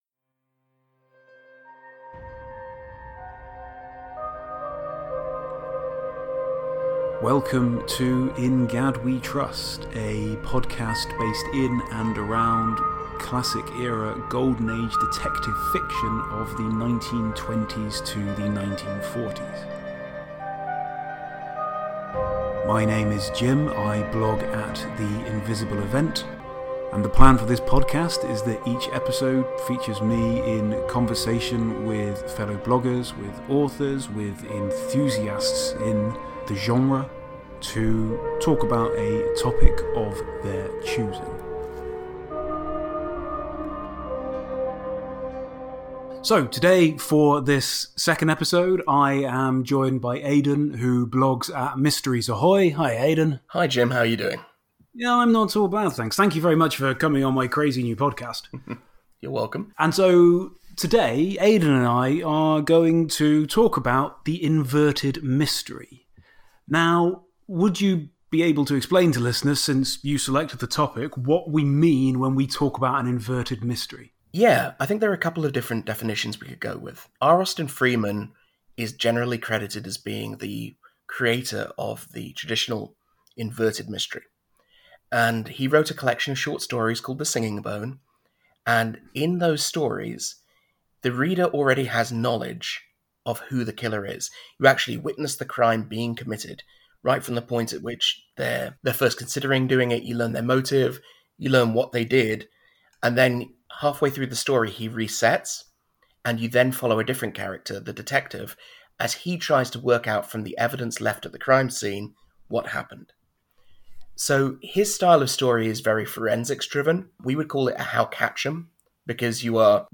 In order to better observe social distancing, we recorded this while in separate countries, with a shift from Zoom to Zencastr as the platform of choice making me wonder why everyone is clamouring for the end of the alphabet when it comes to naming their collaboration software…which we can all reflect on another time.